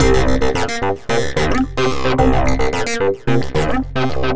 UR 303 acid bass 1 c.wav